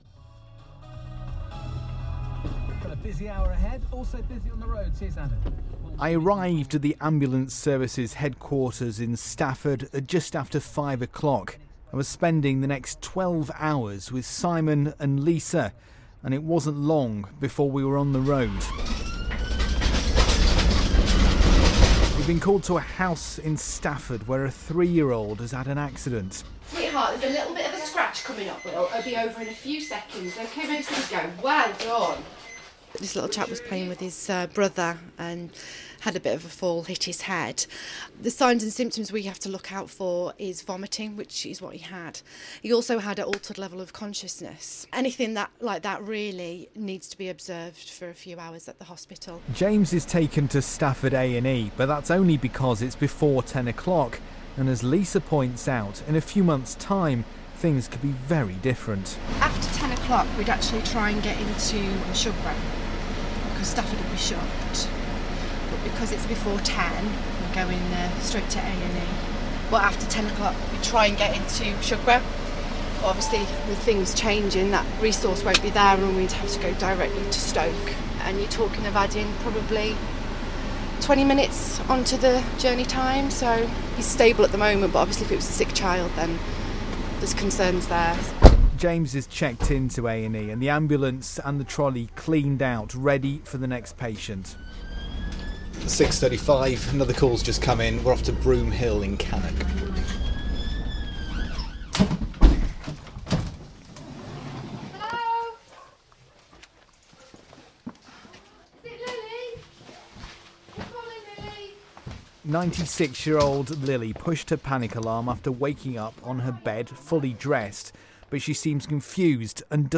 BBC RADIO STOKE: On the night Shift with the West Midland's Ambulance Service PT 1